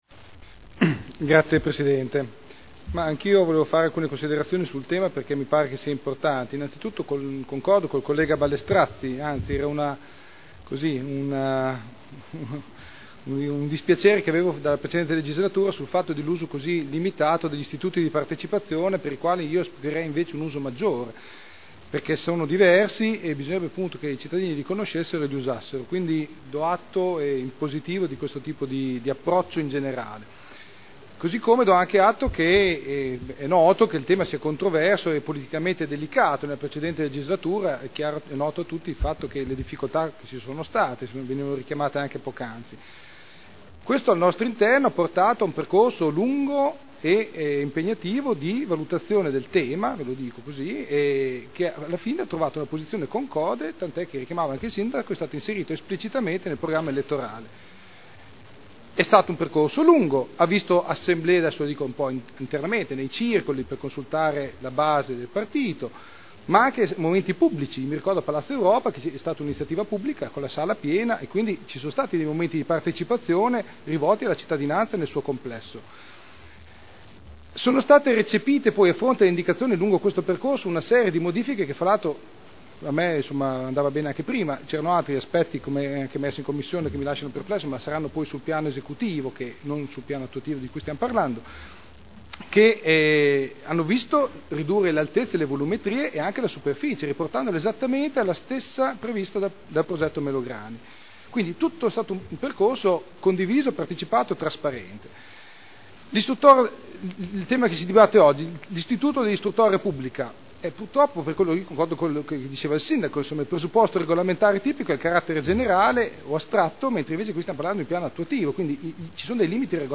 Seduta del 14/12/2009. dichiarazioni di voto